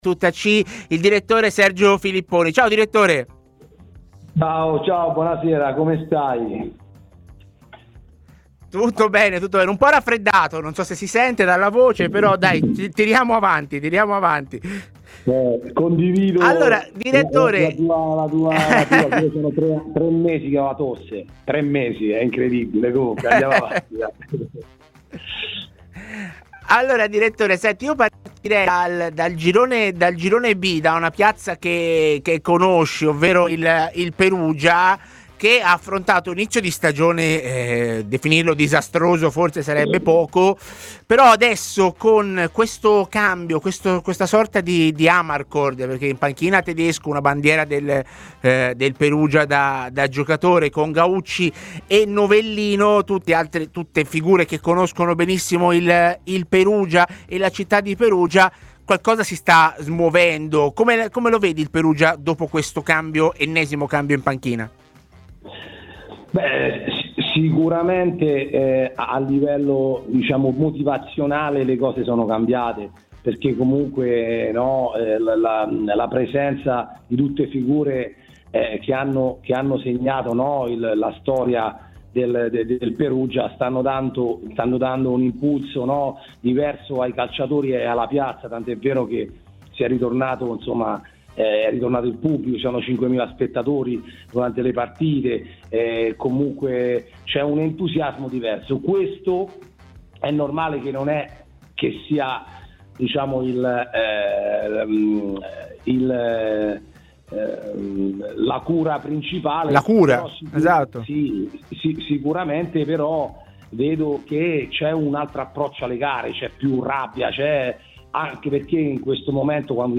TMW Radio